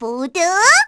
Luna-Vox_Skill3-1_kr.wav